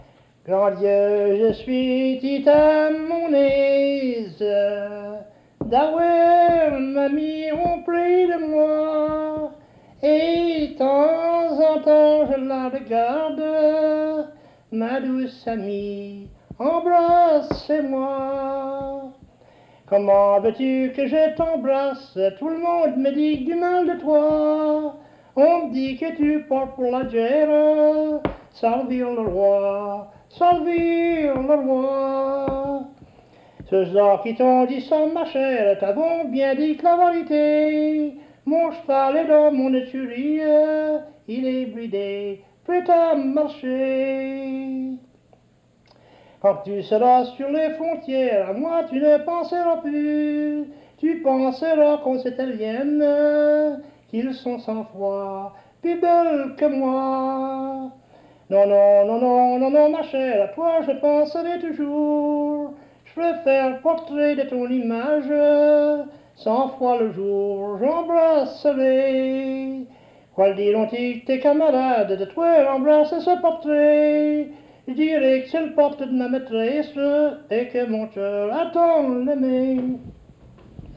Emplacement Cap St-Georges